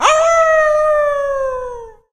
leon_wolf_ulti_vo_01.ogg